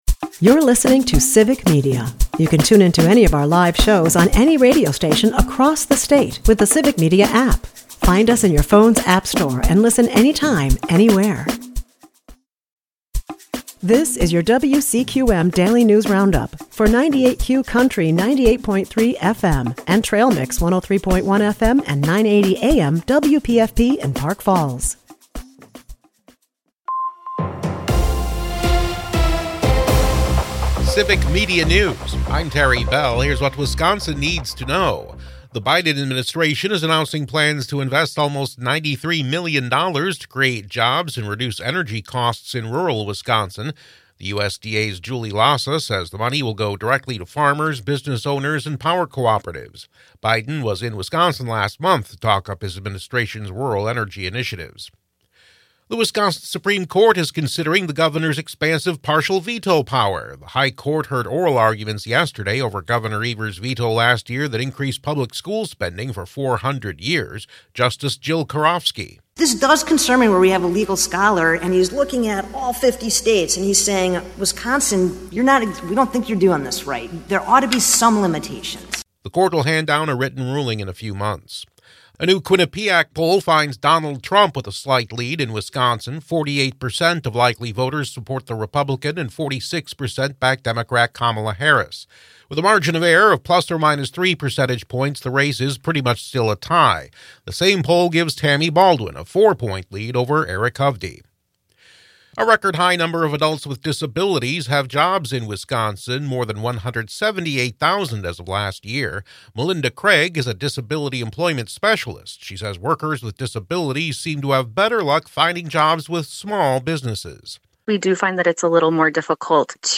98Q Country WCQM and WPFP have your state and local news, weather, and sports for Park Falls, delivered as a podcast every weekday.